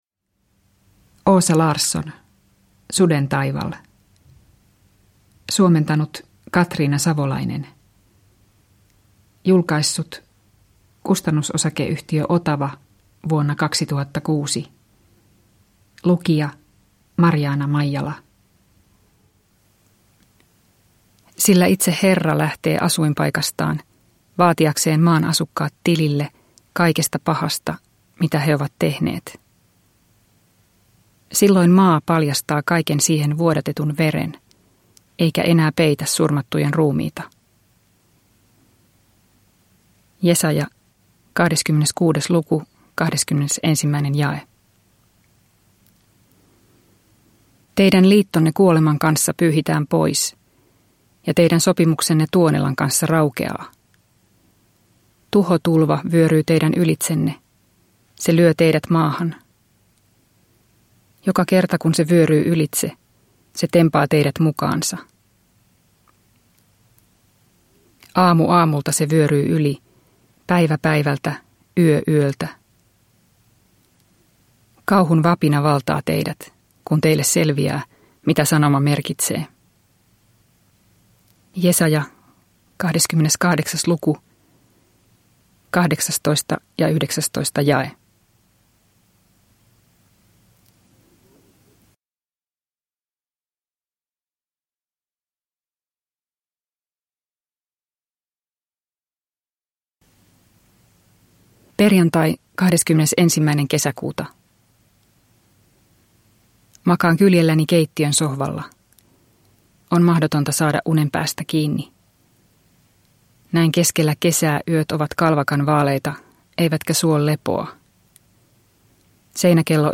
Sudentaival – Ljudbok – Laddas ner